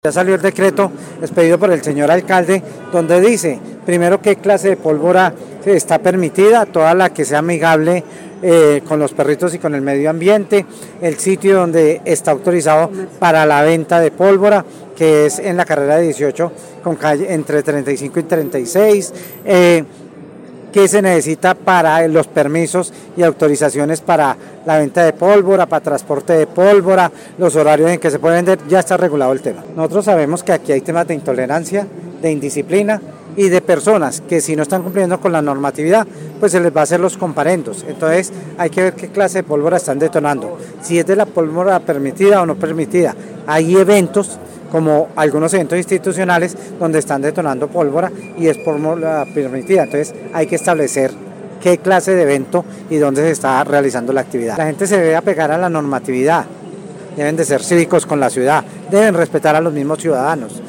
Secretario de Gobierno de Armenia sobre decreto